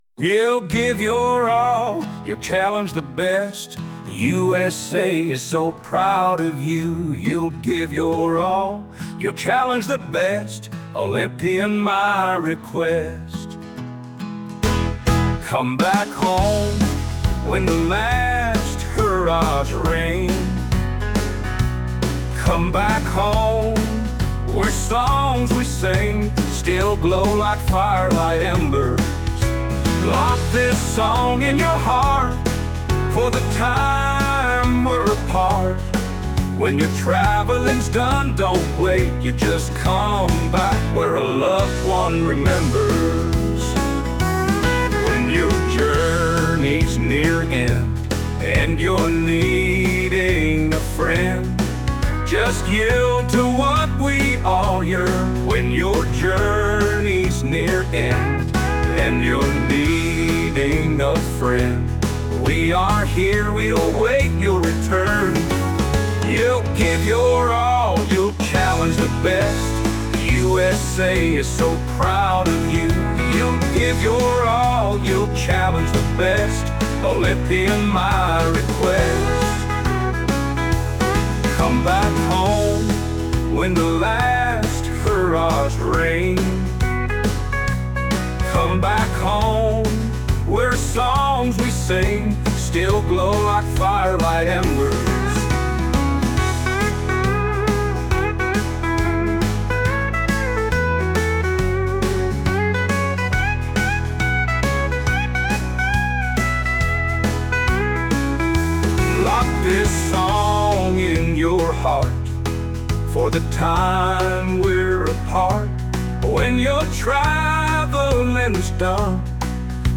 e.g. Olympians  USA  -femme
bluegrass
CBH-USA bluegrass-01.mp3